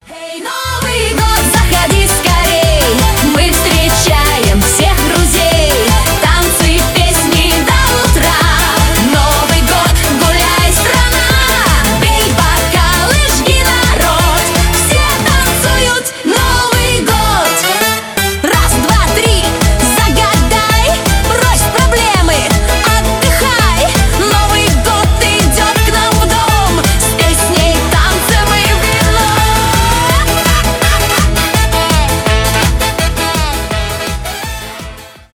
зажигательные , танцевальные
веселые , позитивные